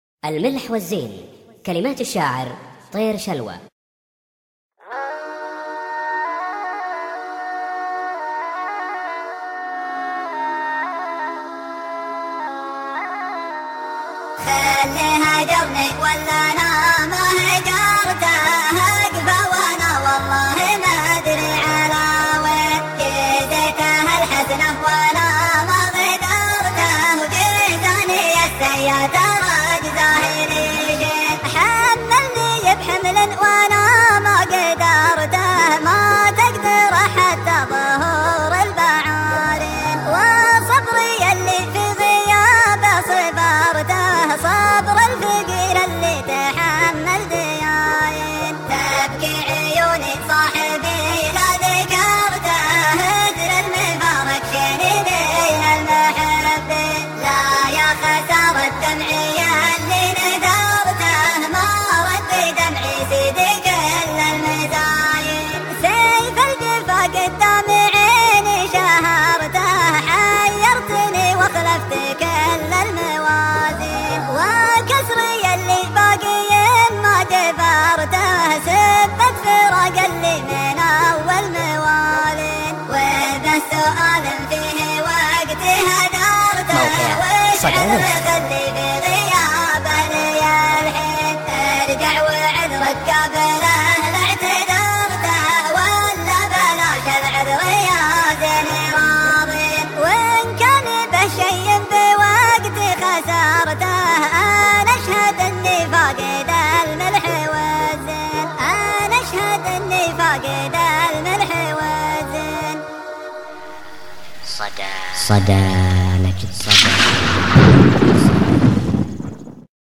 شيلة
بدون ايقاع